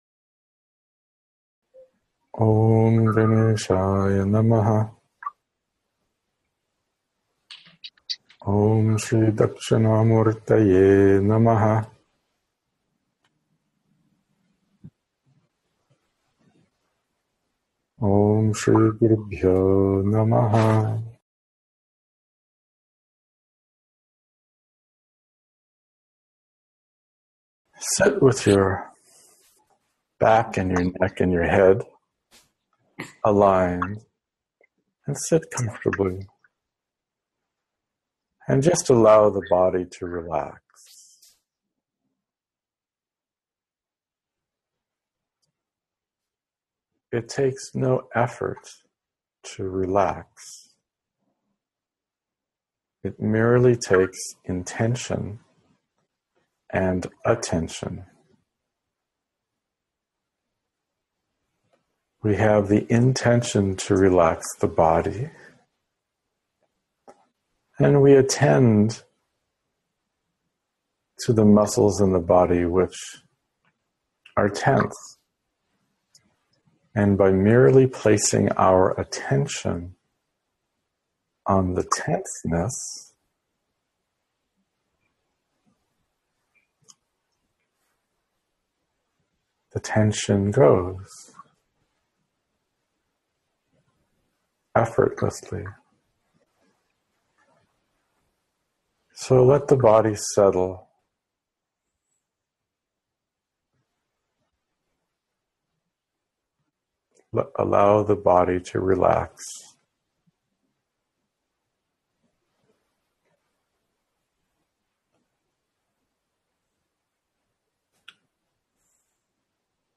PratasSmaranaStotra Guided Meditations - Arsha Drishti